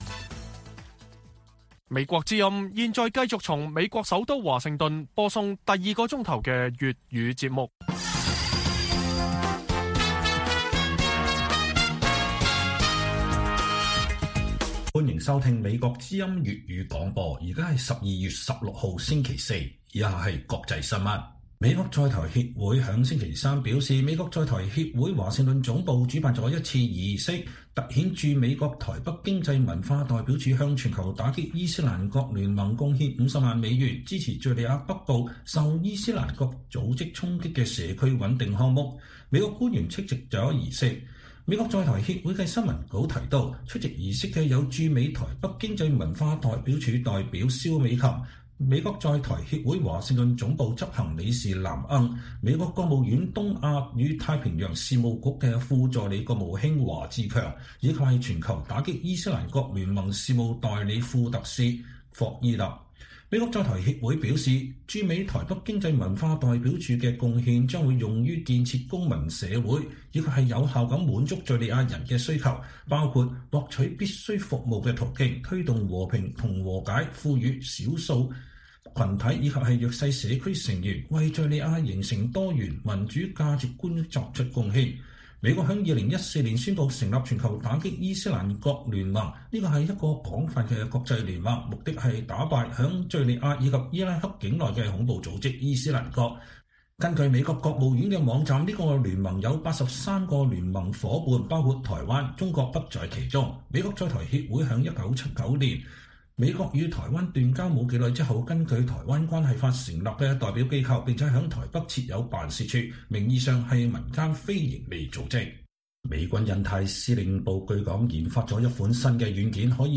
粵語新聞 晚上10-11點: 美軍研發新軟件預測中國對美軍行動的反應